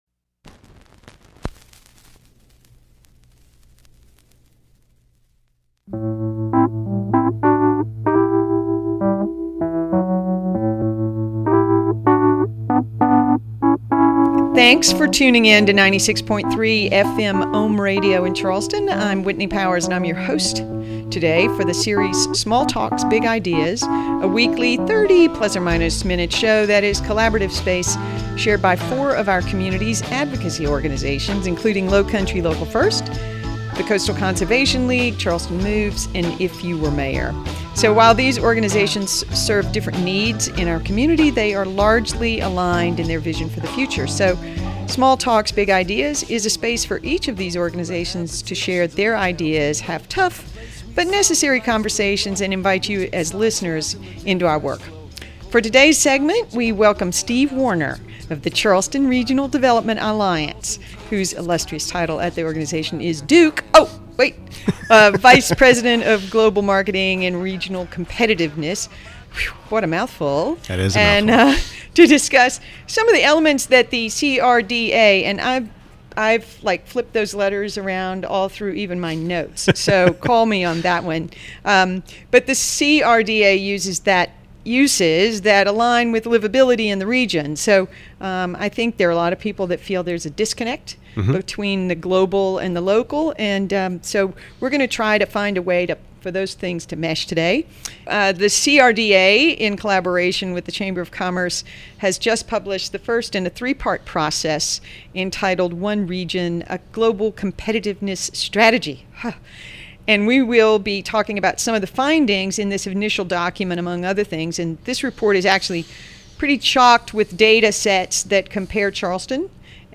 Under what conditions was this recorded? “Small Talks, Big Ideas” is a weekly 30 minute show on WOHM 96.3 FM that is a collaborative space shared by 4 of our community’s nonprofit organizations, including Lowcountry Local First, Coastal Conservation League, Charleston Moves and If You Were Mayor.